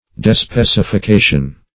Despecification \De*spec`i*fi*ca"tion\, n. Discrimination.